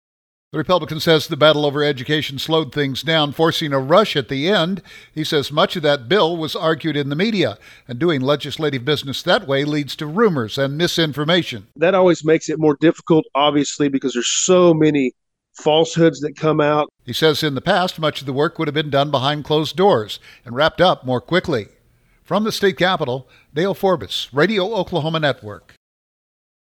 State Representative Ty Burns of Ponca City says the just-completed legislative session had some big differences from the past. Burns said the battle over education slowed things down, forcing a rush at the end.